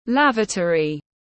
Lavatory /ˈlæv.ə.tər.i/